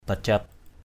/ba-ʤap/ (d.) tràng hạt. E. rosary.